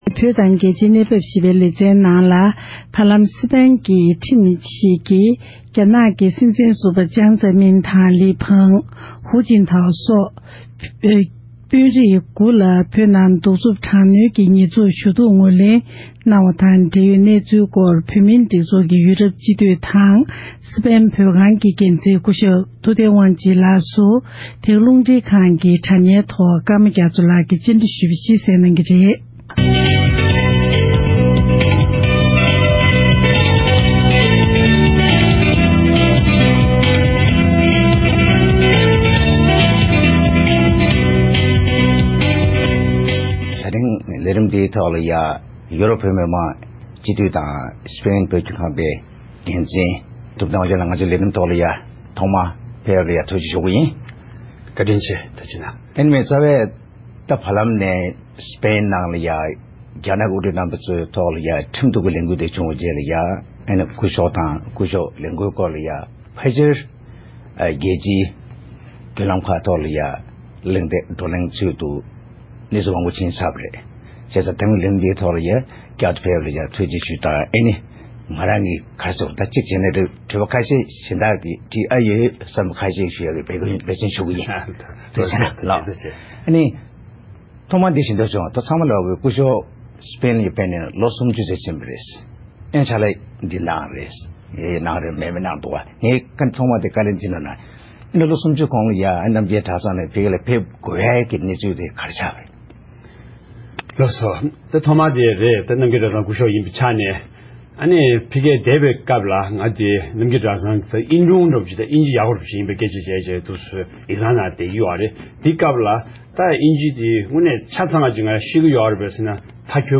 གླེང་མོལ་ཞུས་པར་གསན་རོགས༎